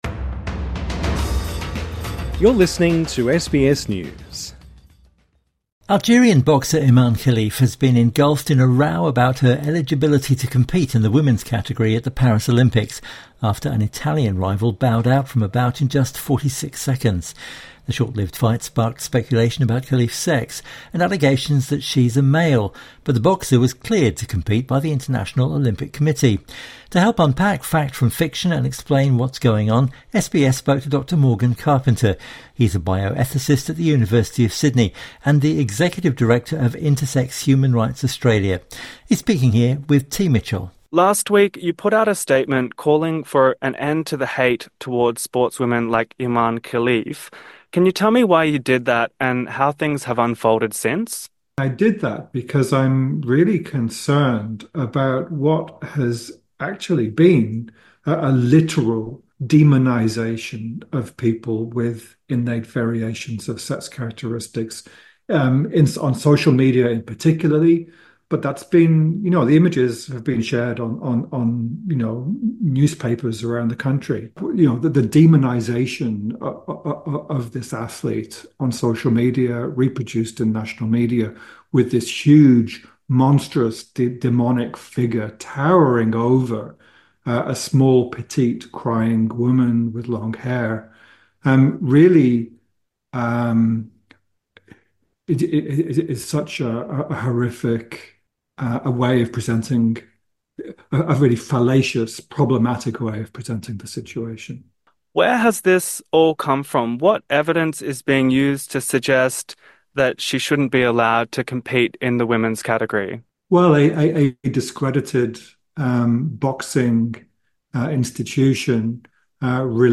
INTERVIEW: What are the facts behind the row over boxer Imane Khelif's gender?